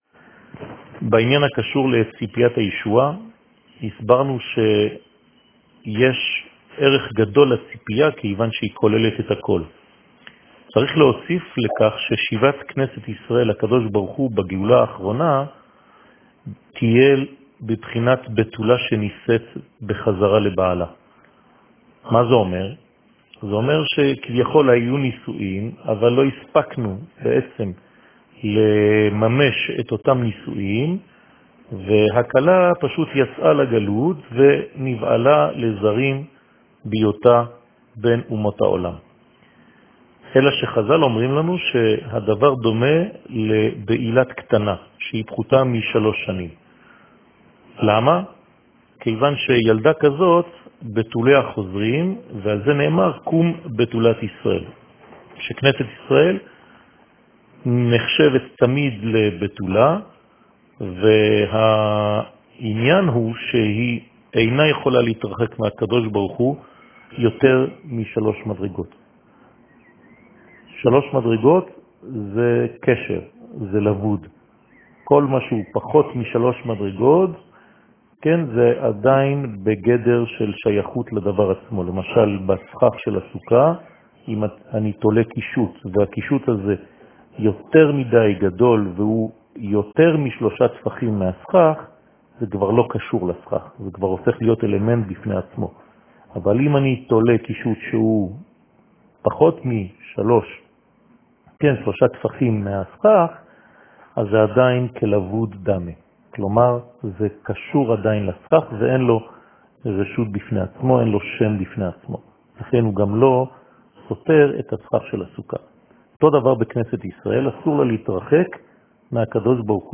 שיעור מ 13 יולי 2020
שיעורים קצרים